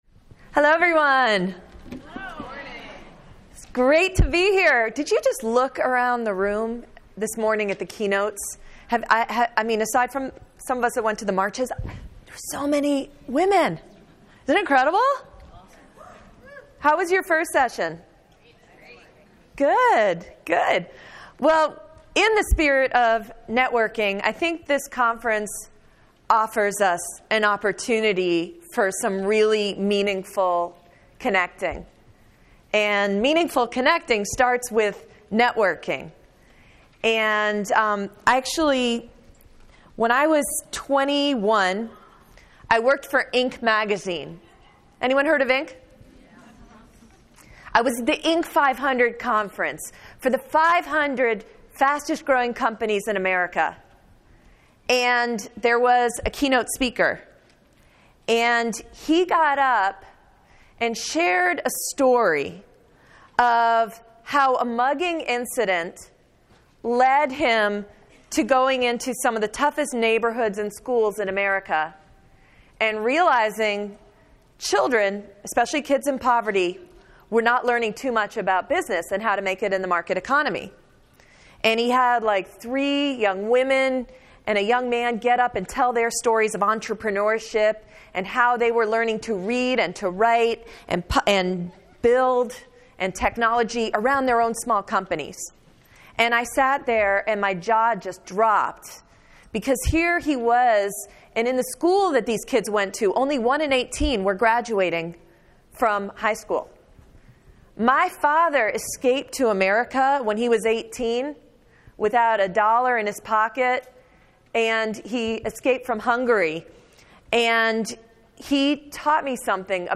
Click Play below to listen to this session from the 2017 conference.